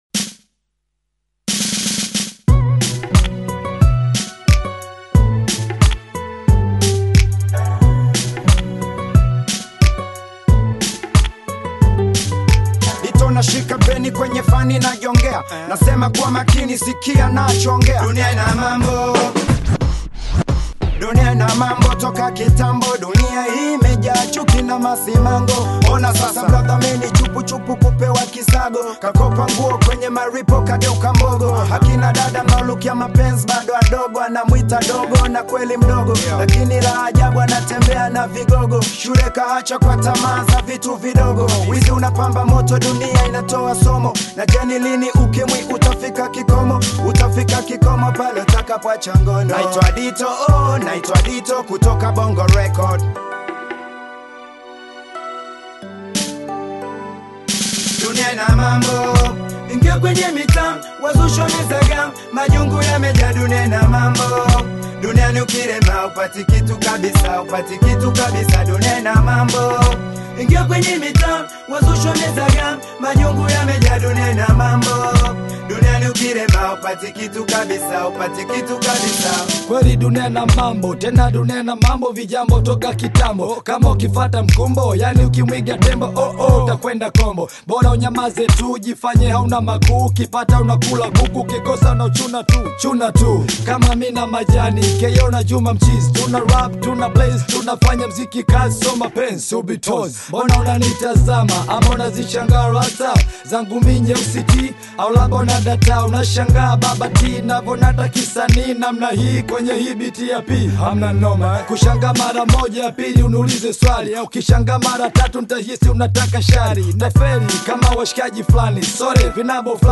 With thought-provoking lyrics and a captivating beat